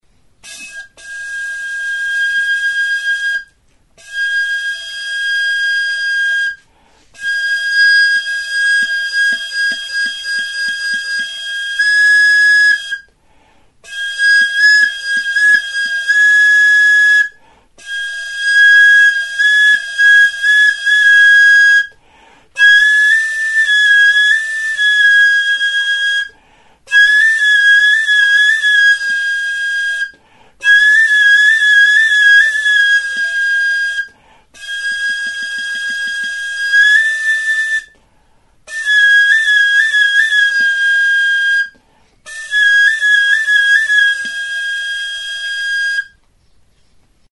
Aerophones -> Flutes -> Fipple flutes (two-handed) + kena
Recorded with this music instrument.
Banbuzko tutua da. Goiko muturrean moko flauta motako ahokoa du. Tonu aldaketarako 4 zulo ditu aurrekaldean. Beheko muturra itxia du.